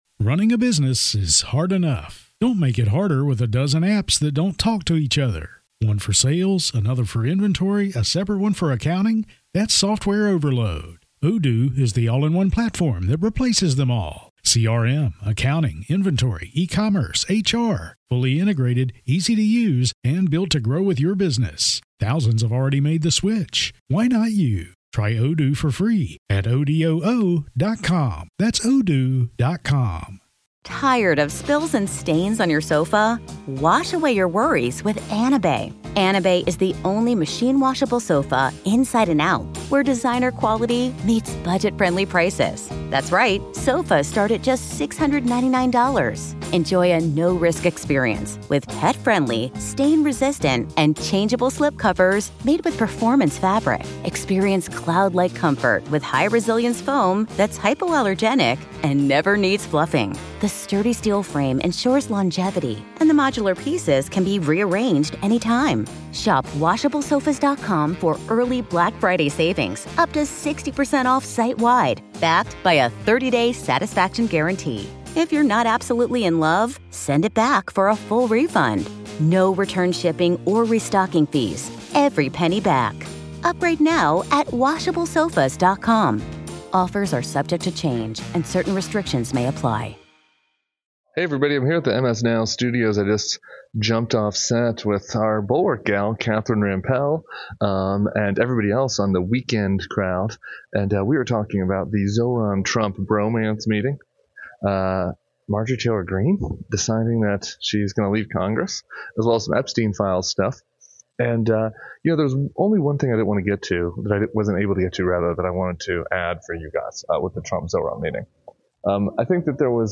Tim Miller join MSNOW’s The Weekend (with The Bulwark’s own Catherine Rampell) to discuss Trump’s weird Mamdani meeting, why MTG suddenly bailed on Congress, and what the looming Epstein Files release could shake loose.